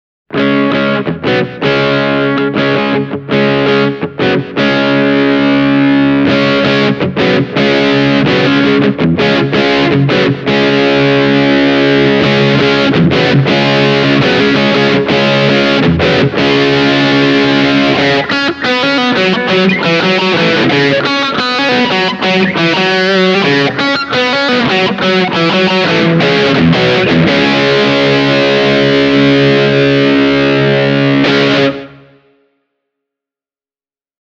Using Tight mode and humbuckers will give you a modern distortion tone:
bogner-burnley-t-e28093-hamer.mp3